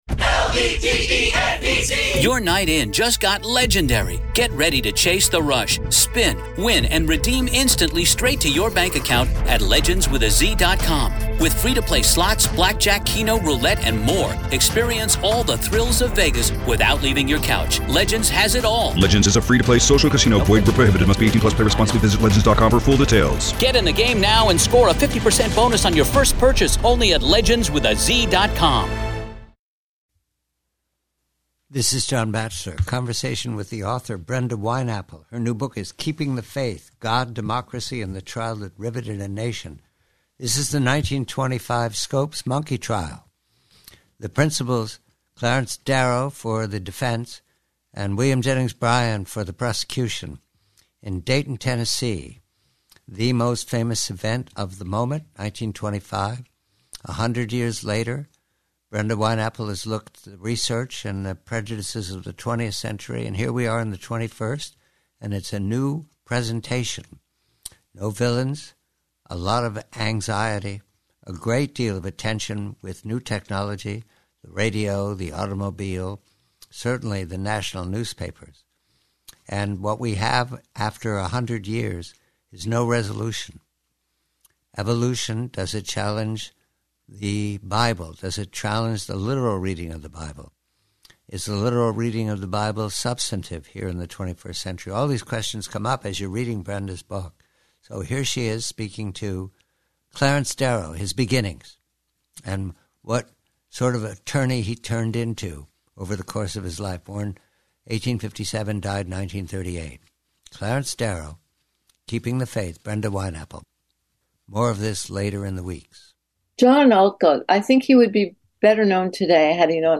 Preview: Scopes Trial: Conversation with author Brenda Wineapple, "Keeping the Faith," regarding the famous Scopes Monkey Trial of 1925 and the personality and operatic style of Clarence Darrow.